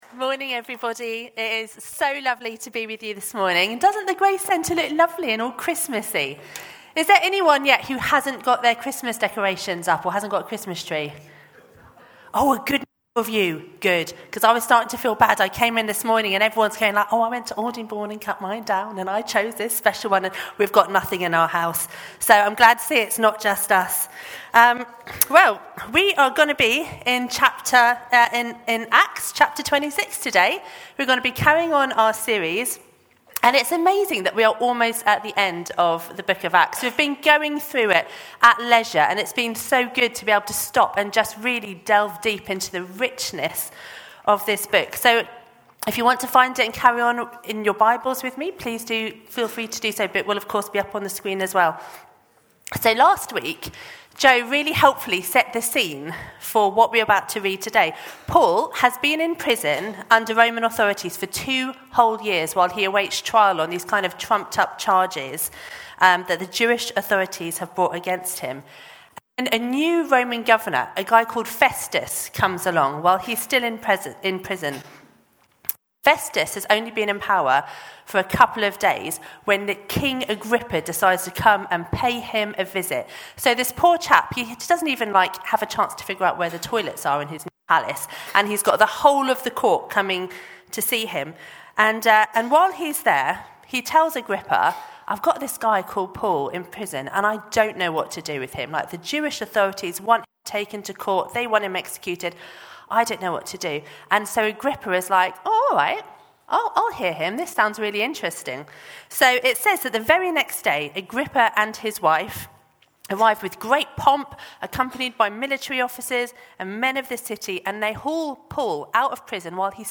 Grace Church Sunday Teaching Do You Believe?